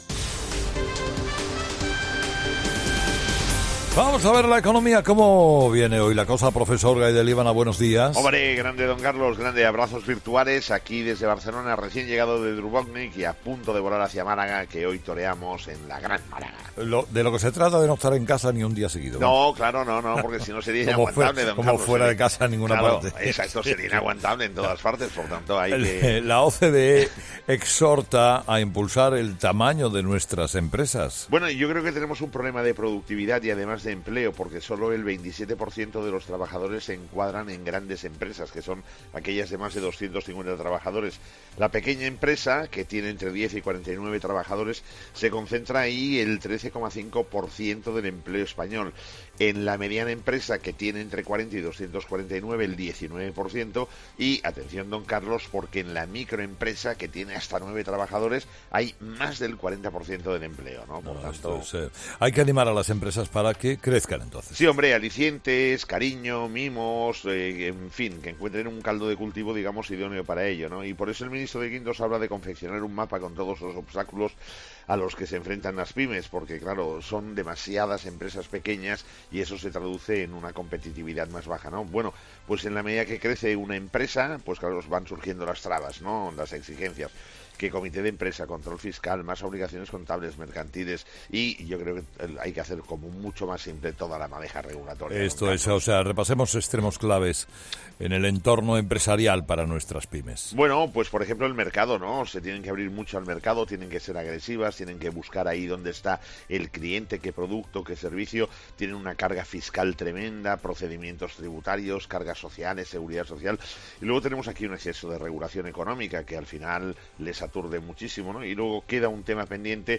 Todas las mañanas a las 7.40 la actualidad económica con el profesor Gay de Liébana en 'Herrera en COPE'.